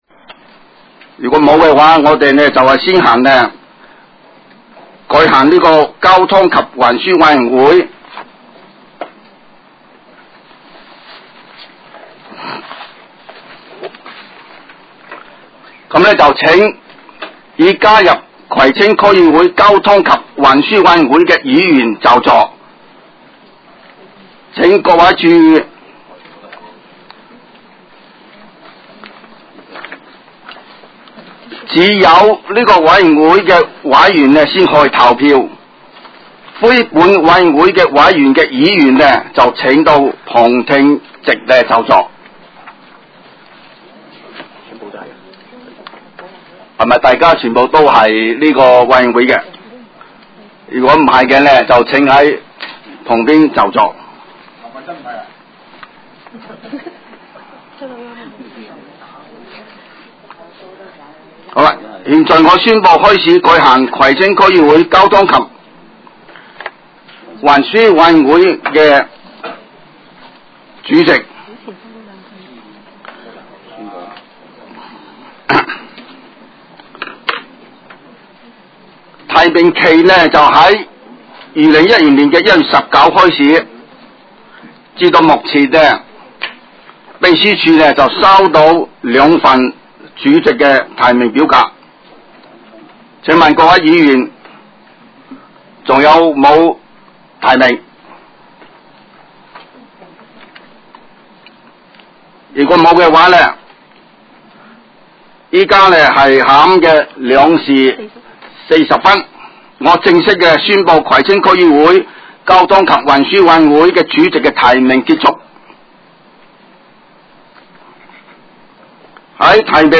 委员会会议的录音记录
地点: 香港葵涌兴芳路166-174号 葵兴政府合署10楼 葵青民政事务处会议室